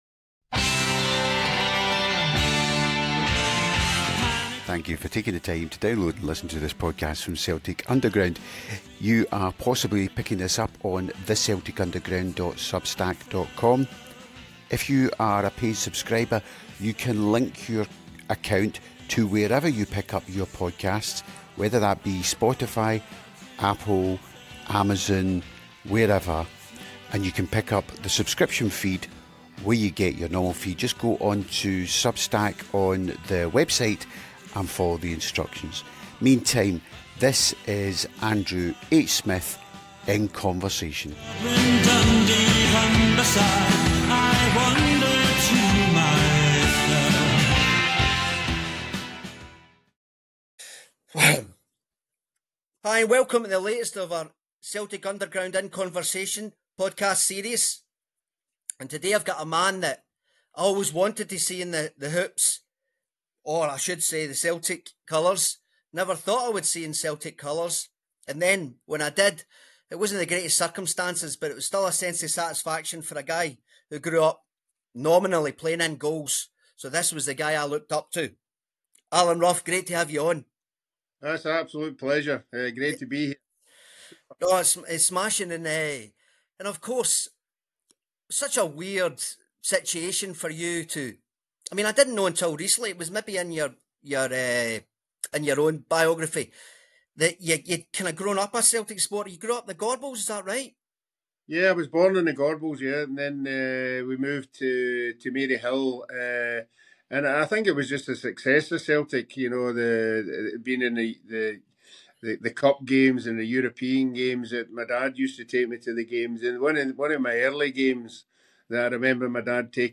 in conversation with...Alan Rough